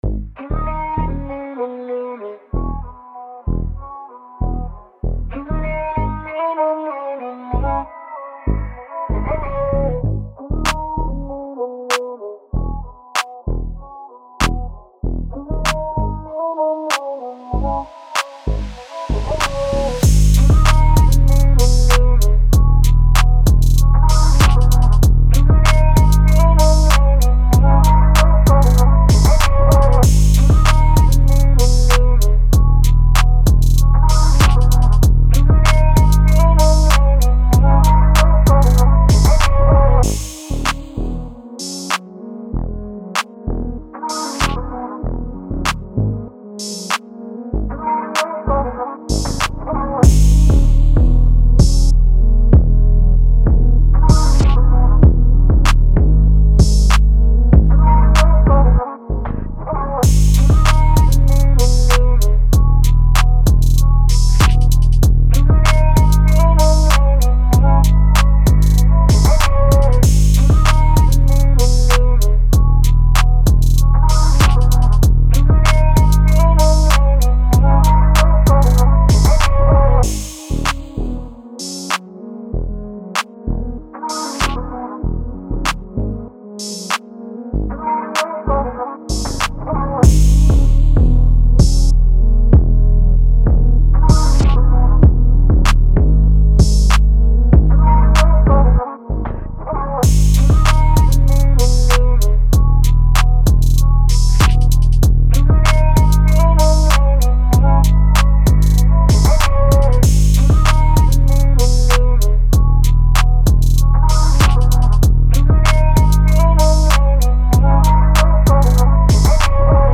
Hip Hop, Pop, Dance
G# Min